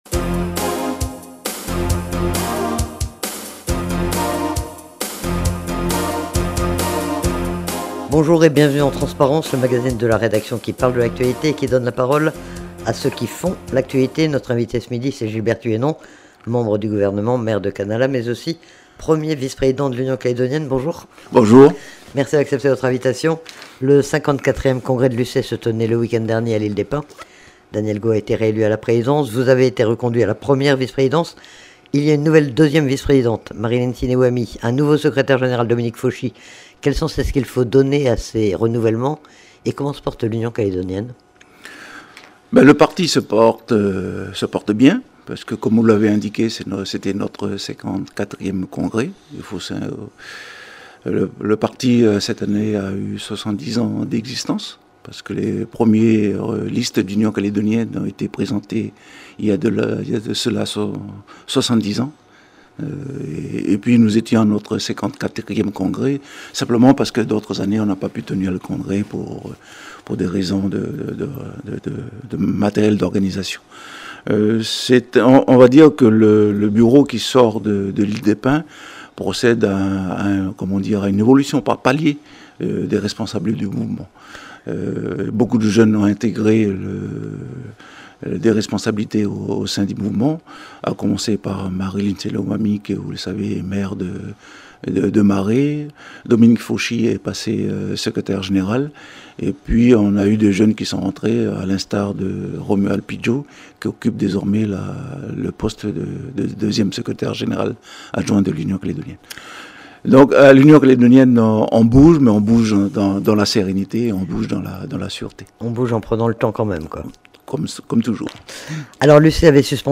Sous sa casquette de membre du gouvernement en charge de la fiscalité, il a été également interrogé sur la situation économique et sur les réformes engagées.